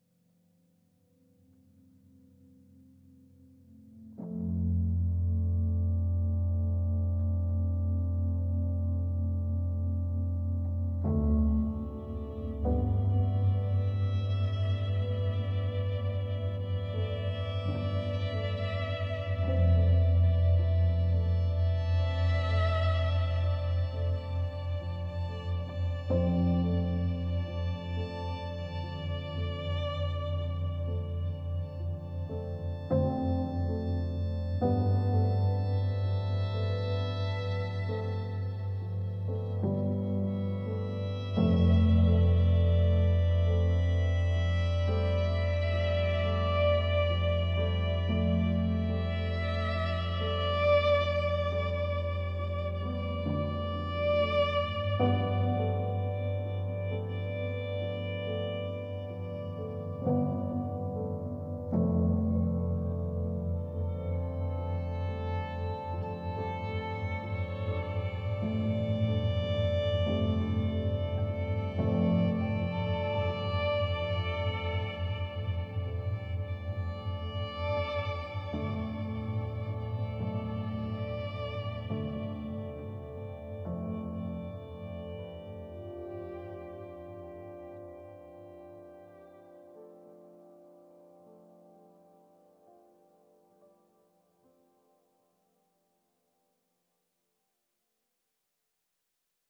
ambient # piano # classical